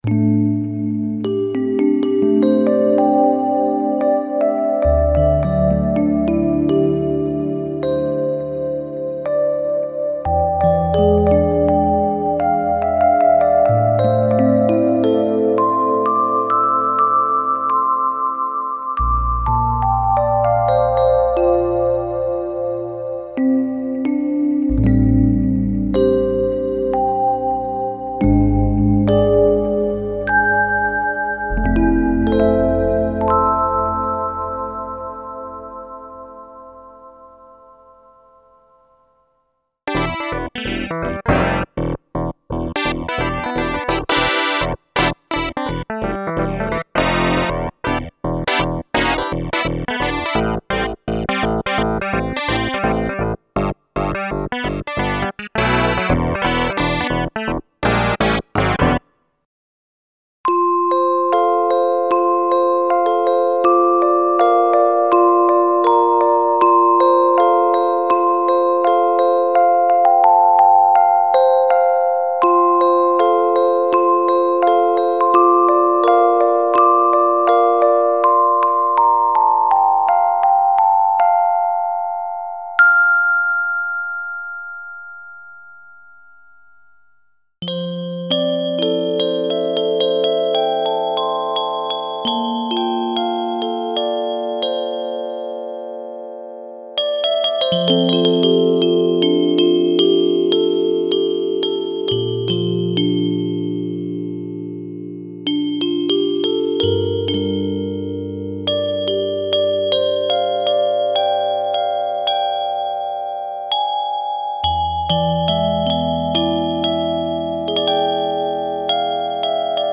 Electric pianos, clavinets and FM piano emulations for various music styles.
Info: All original K:Works sound programs use internal Kurzweil K2500 ROM samples exclusively, there are no external samples used.
K-Works - Electro Volume 1 - LE (Kurzweil K2xxx).mp3